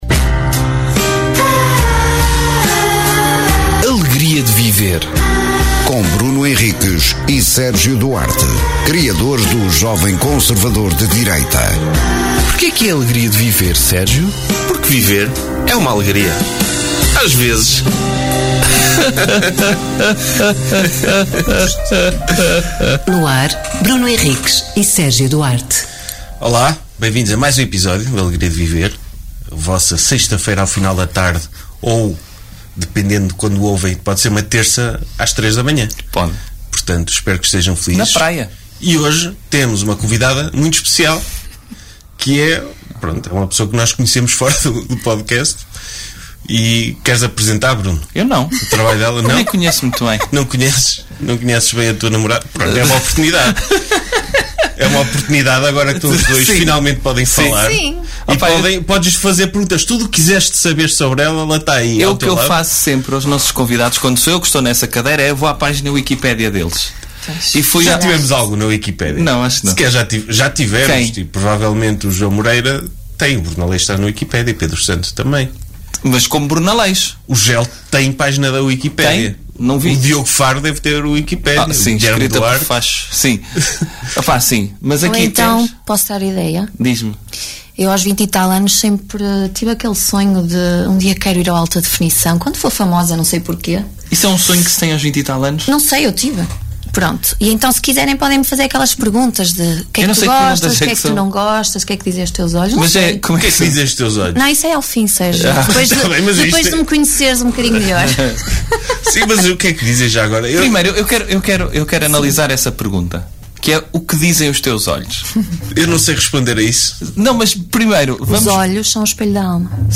Sem preparação ou discussão prévia, abrem o microfone e partem em cada episódio para um tema desconhecido; que exploram com humor e sentido crítico… Uma hora transmitida em direto e sem filtros, que não poucas vezes terá convidados, estejam no estúdio ou fora dele…